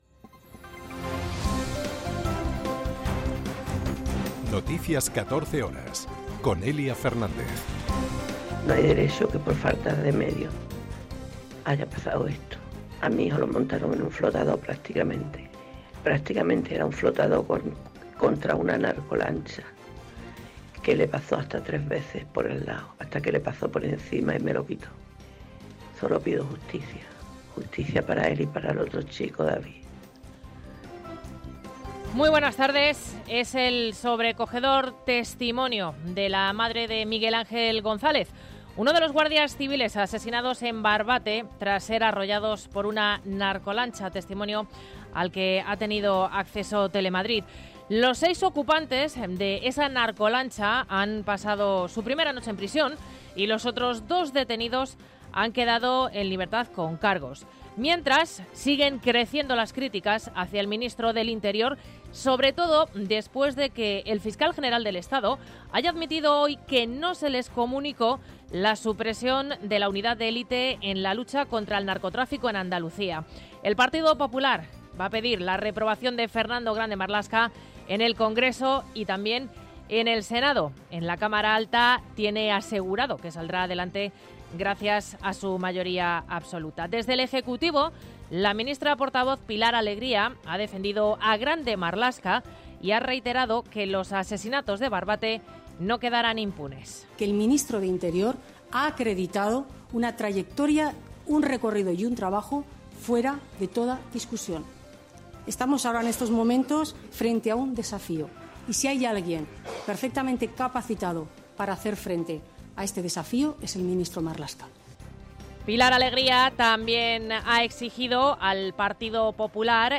en España y en el mundo. 60 minutos de información diaria con los protagonistas del día y conexiones en directo en los puntos que a esa hora son noticia.